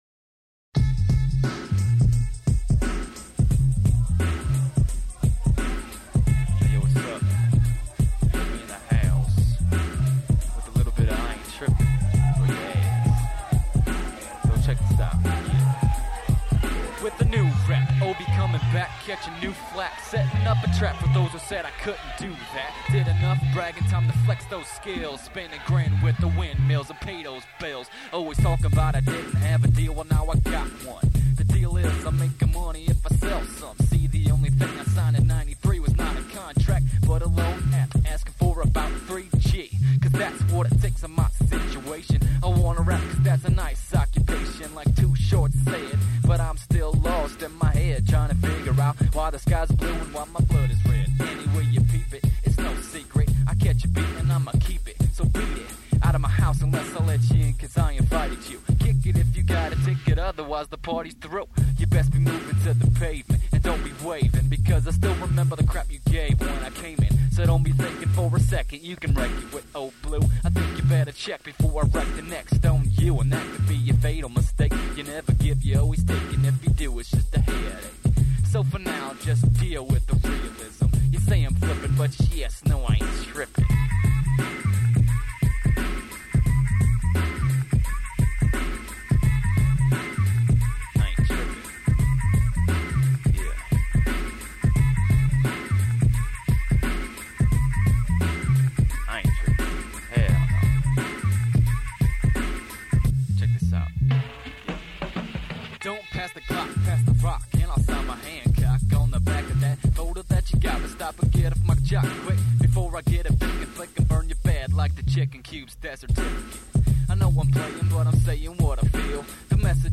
Kinda laid back, kinda nice.